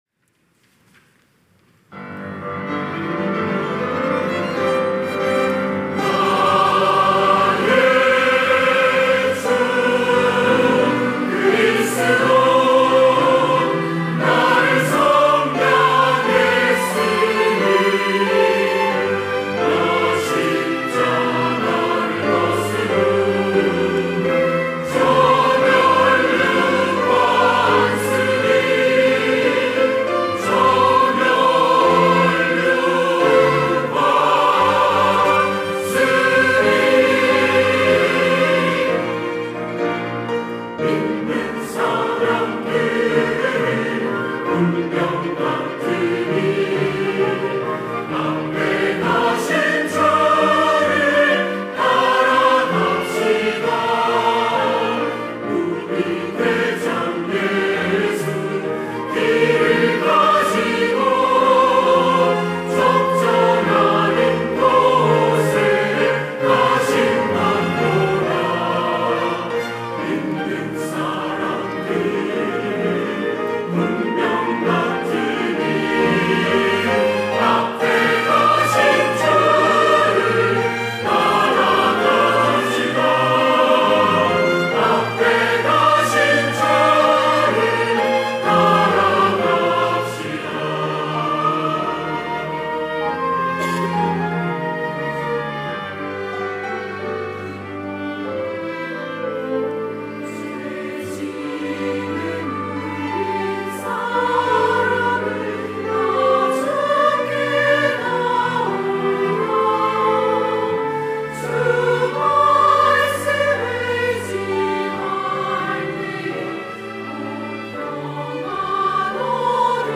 할렐루야(주일2부) - 믿는 사람들은 군병 같으니
찬양대